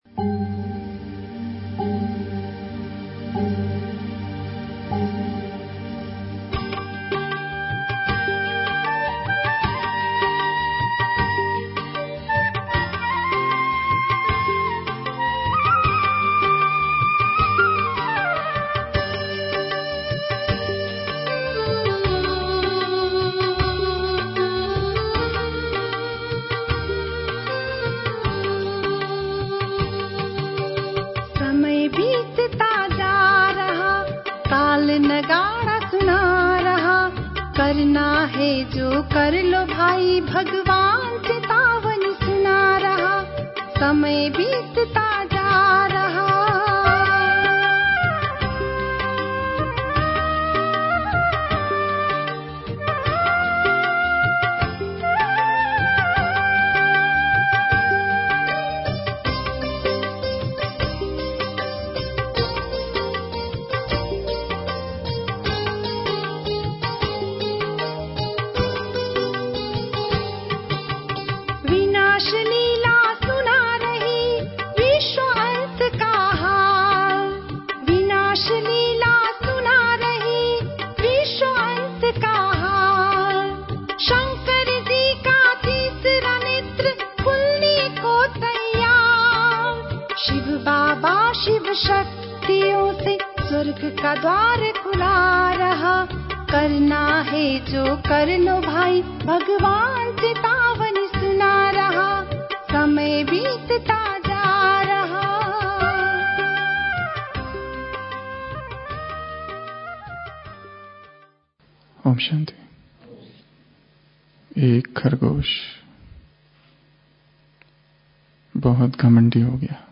My Divine Valentine (AM 13.02.1992 Revision) Special Murli on Valentine Day (Rajrishi Hall, GS) Speaker